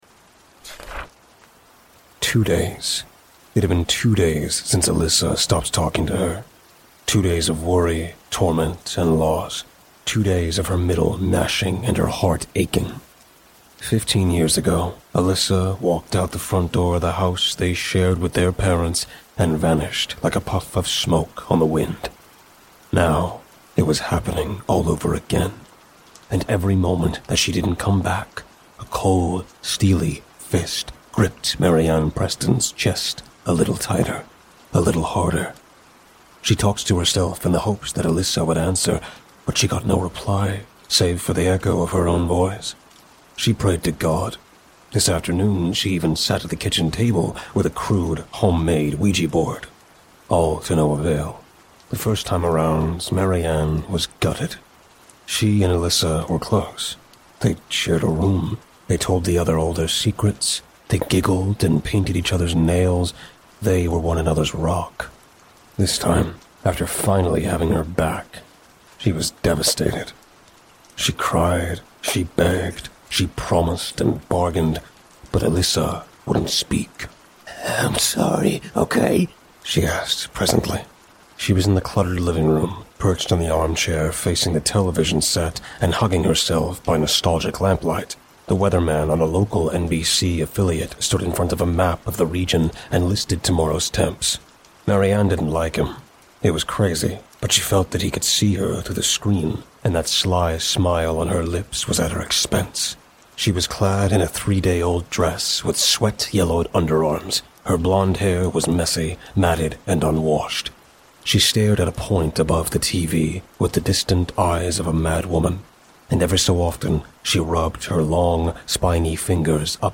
Body Seizures, Convulsing, Convulsions, Grand Mal, Tonic-clonic - A body shaking on the floor